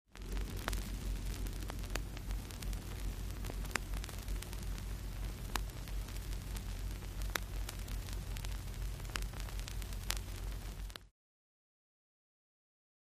Record Static: Constant Crackles.